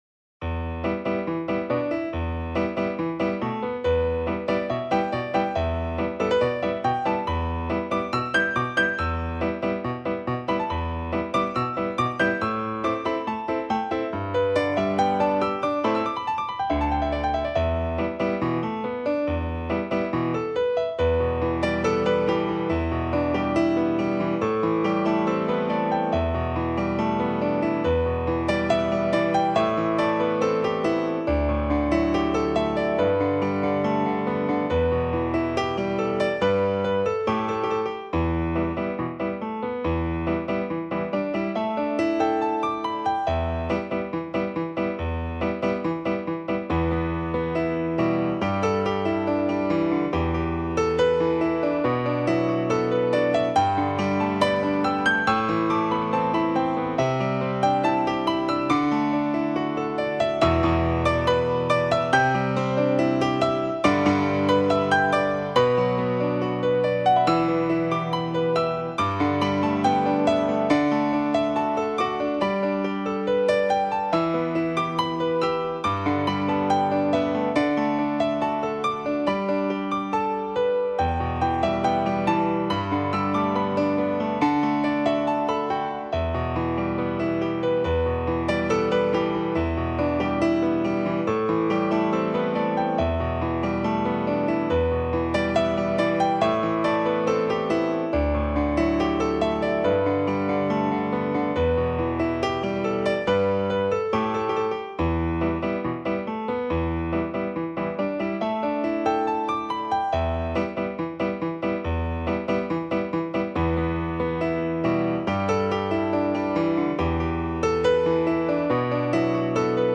钢琴的音质素来明净如水，它很容易吹走尘世浮华。
蒙古族歌曲